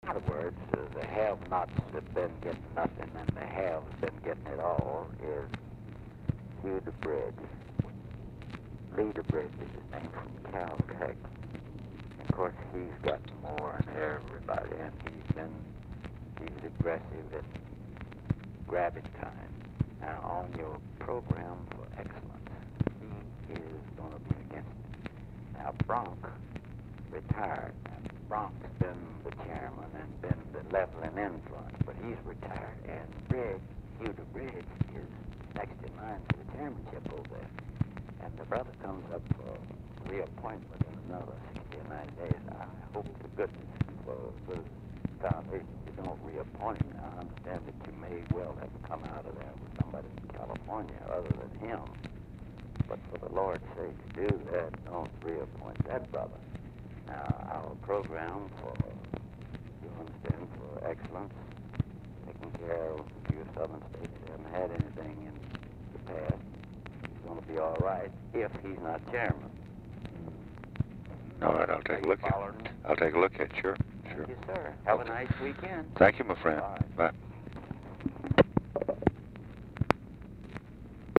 Telephone conversation # 3101, sound recording, LBJ and UNIDENTIFIED MALE, 4/22/1964, time unknown | Discover LBJ